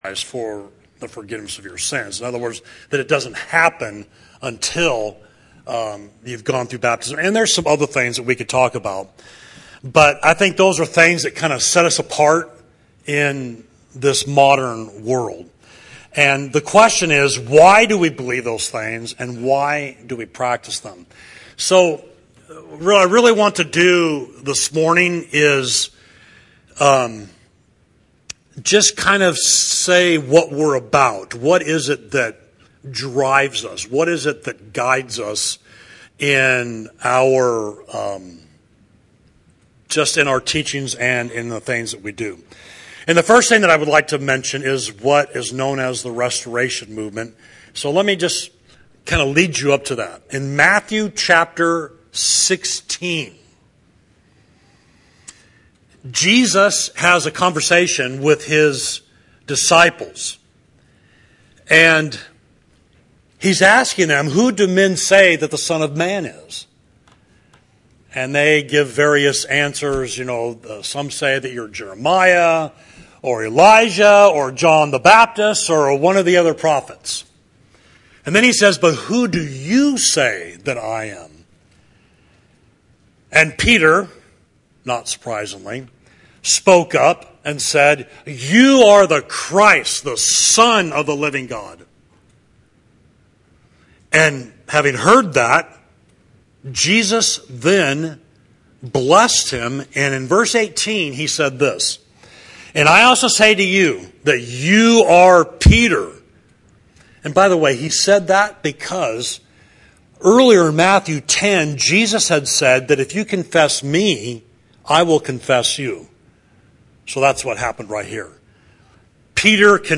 March 17, 2024: Morning Savage Street Worship Service; and Sermon: “The Churches of Christ”